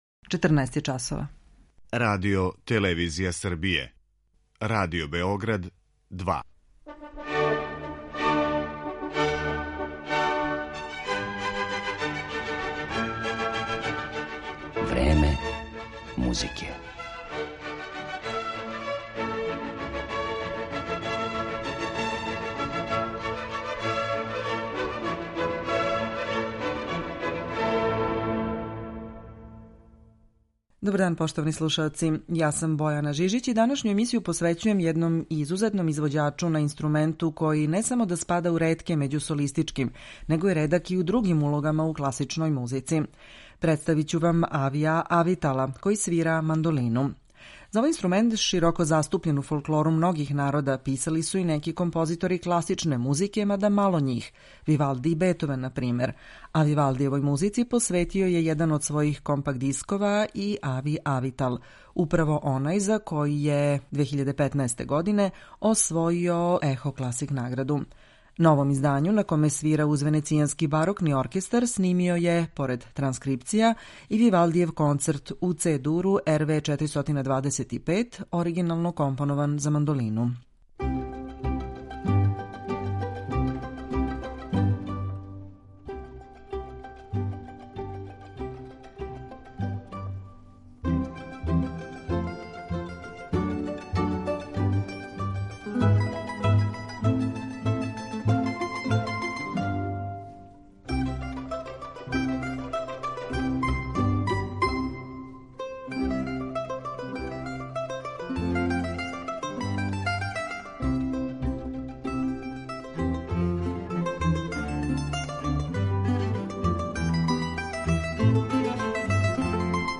Данашња емисија посвећена је изузетном извођачу на инструменту који се не среће често у класичној музици - на мандолини.